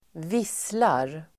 Uttal: [²v'is:lar]